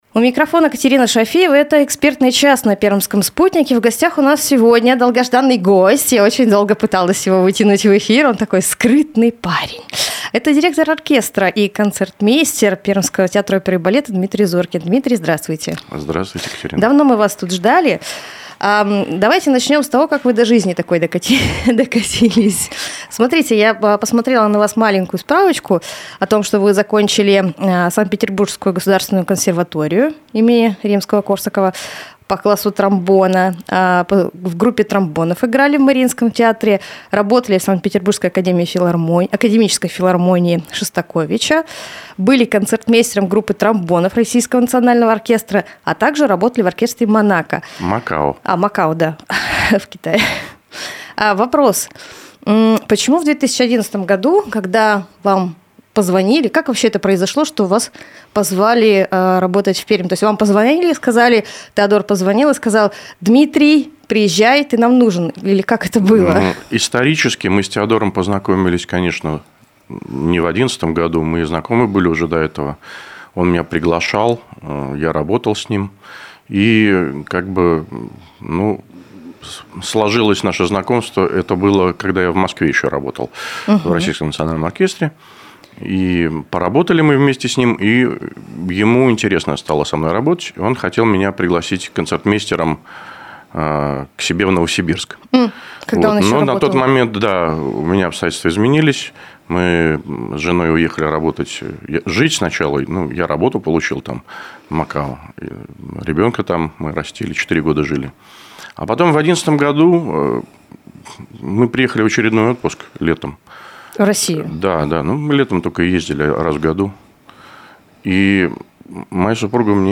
Аудиоинтервью - Пермский театр оперы и балета